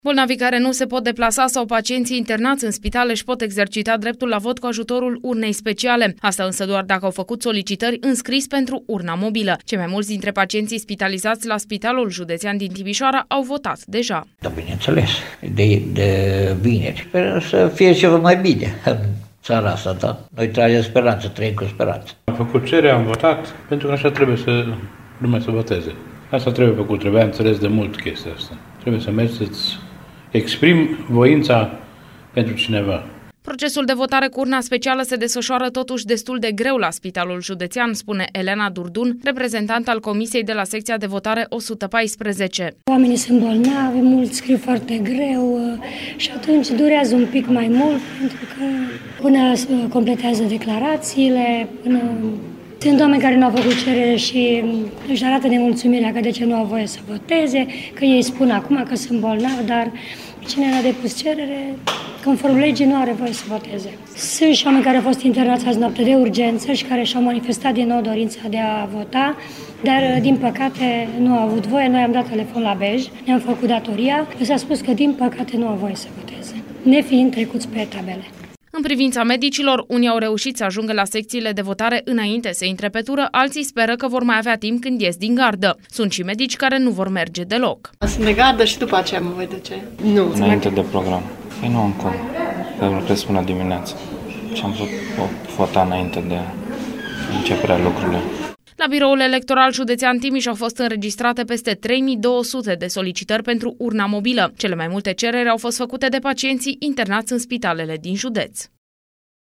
A stat de vorbă cu pacienții și medicii de la Spitalul Județean din Timișoara